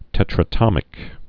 (tĕtrə-tŏmĭk)